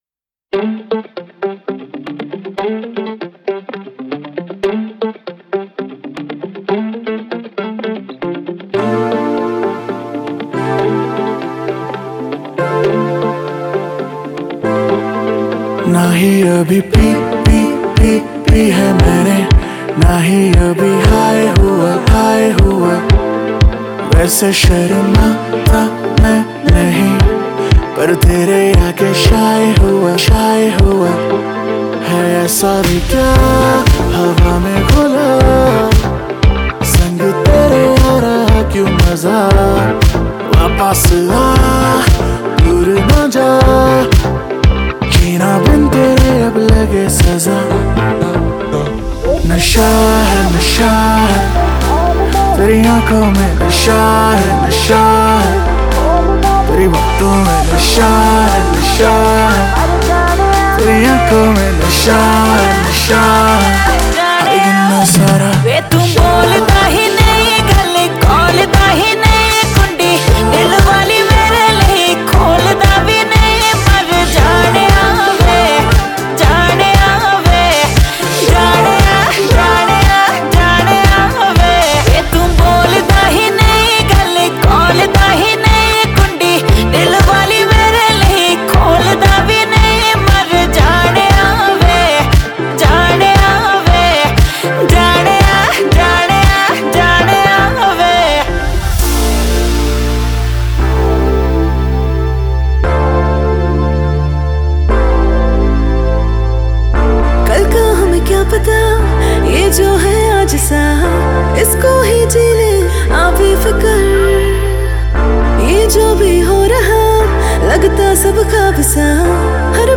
آهنگ شاد هندی